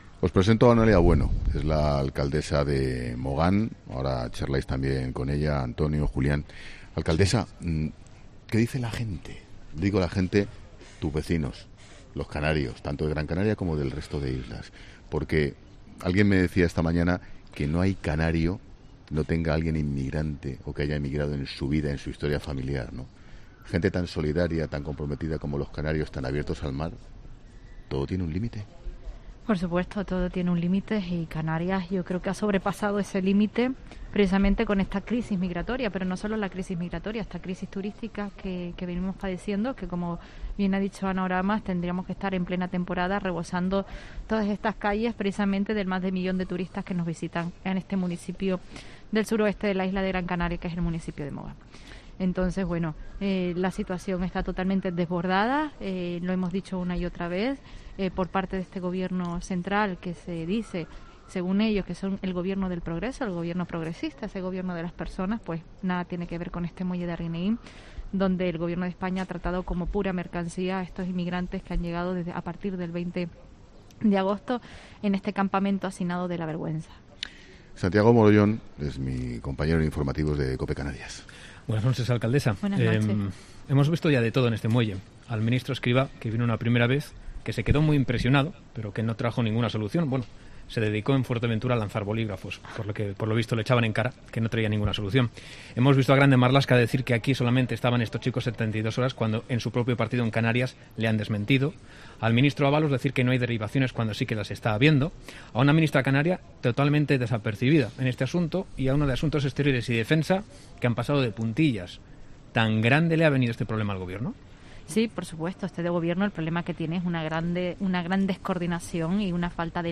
Hoy, ‘La Linterna’ se ha encendido desde Arguineguín, en Mogán.